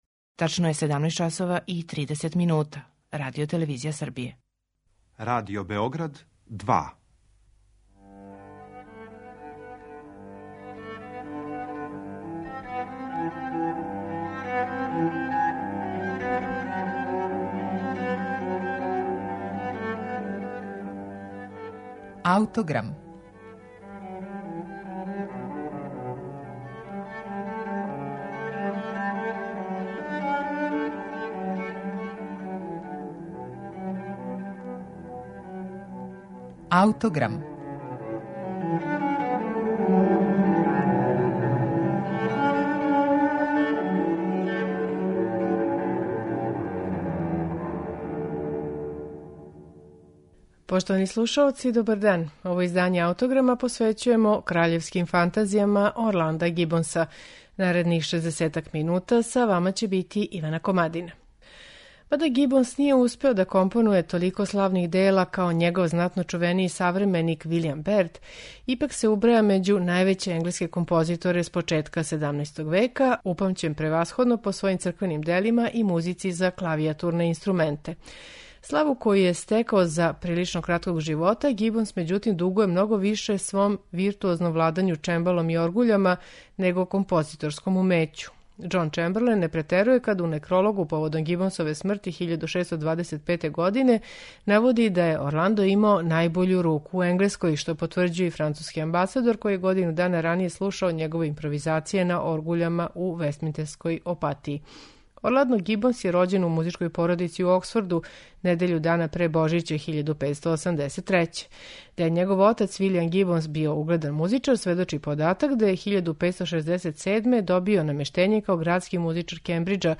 Данашњи Аутограм смо посветили Гибонсовим "Краљевским фантазијама", композицијама писаним за ансамбле од два до шест извођача, делима у којима је Гибонсова композиторска машта нашла подручје на којем ће се најслободније изразити.
Тринаест Гибонсових фантазија слушаћете у тумачењу које су на оригиналним инструментима 16. и 17. века остварили
алт и тенор виола
позитив оргуље